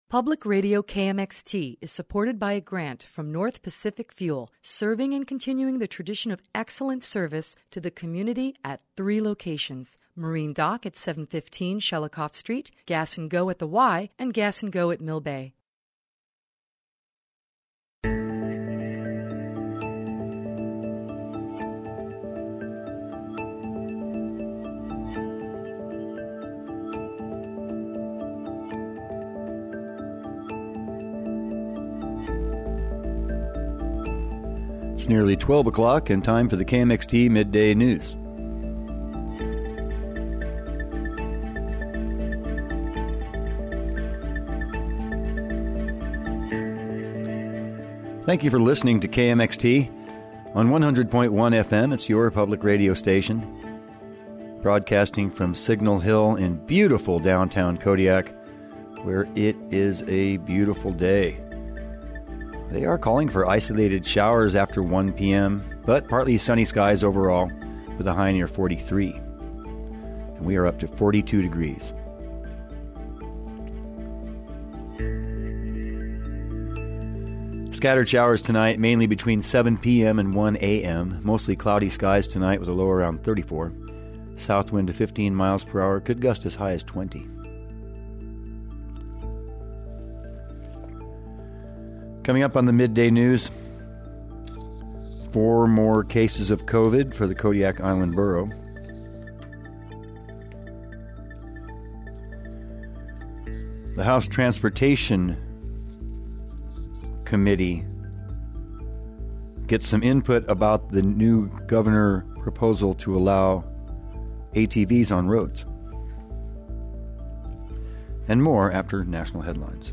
KMXT Staff April 12, 2021 News, Newscasts, Newsflash